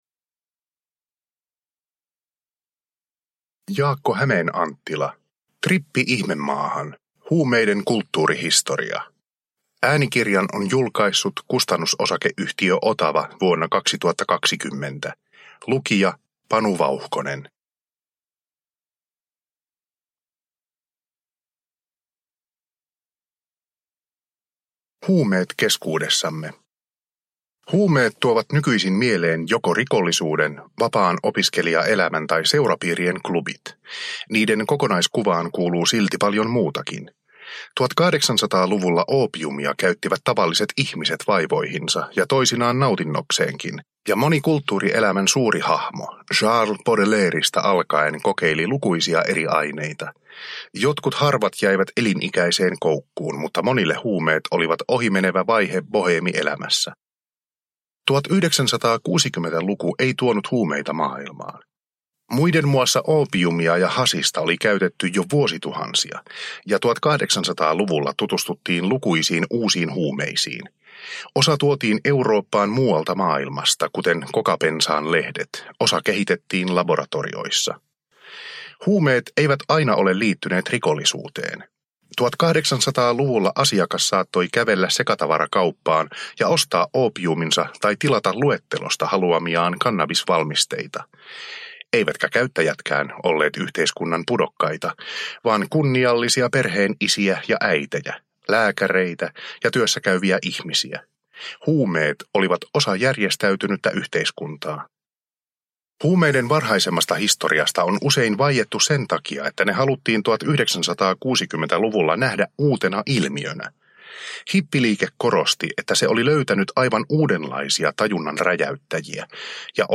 Trippi ihmemaahan – Ljudbok – Laddas ner